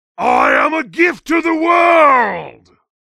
Saxton Hale responses
Licensing This is an audio clip from the game Team Fortress 2 .